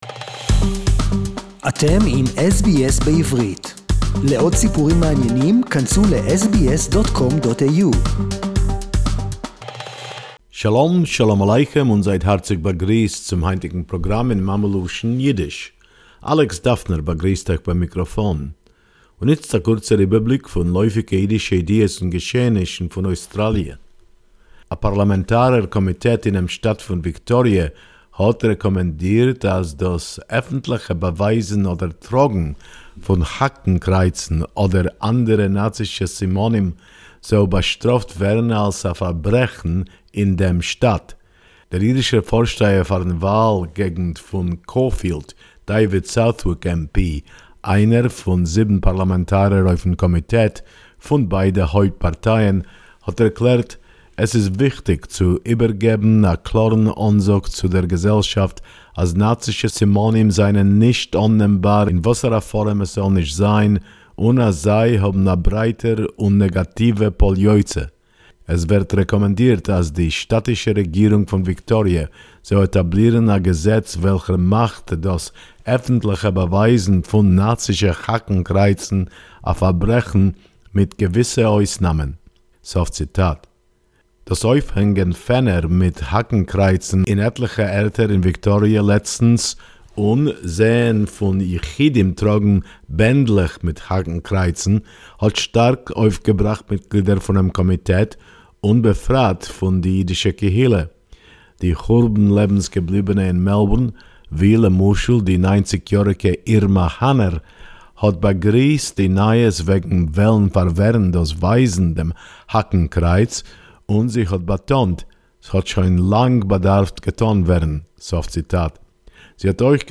A new Tasmanian Holocaust Museum...SBS Yiddish report